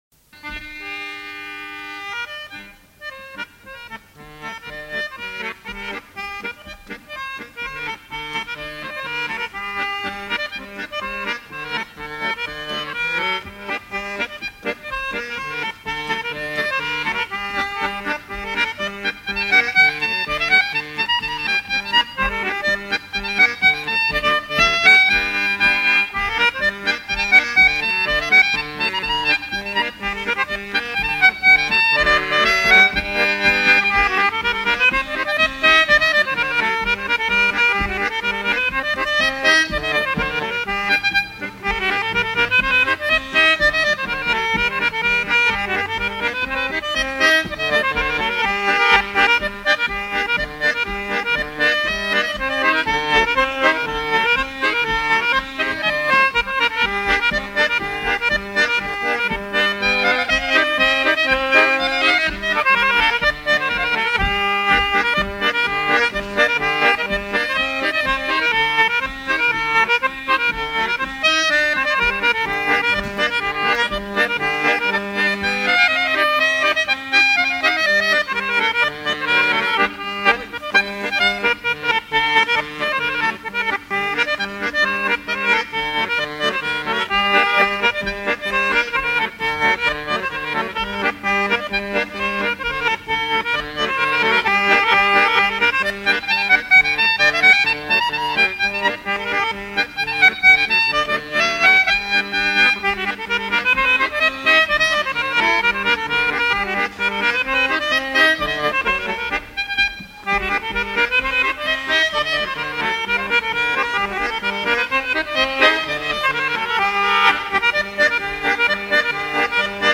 polka de Carélie
danse : polka
Pièce musicale éditée